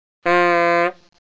bocina
bocina.mp3